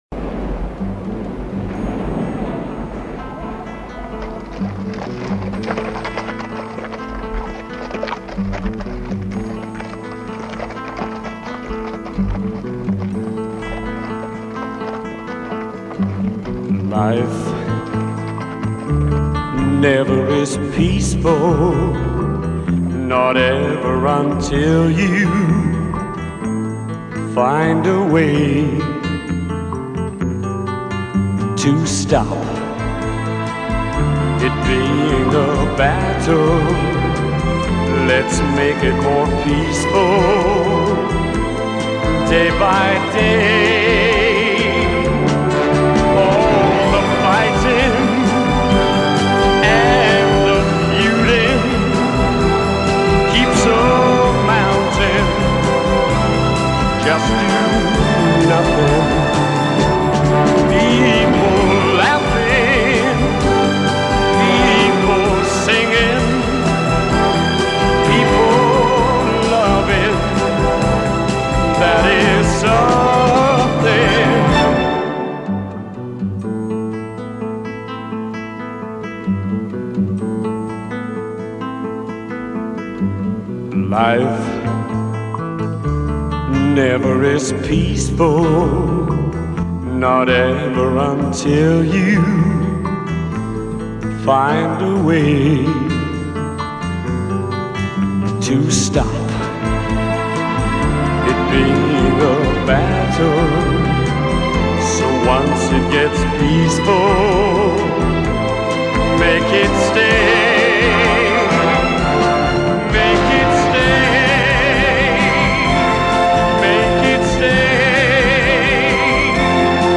The final song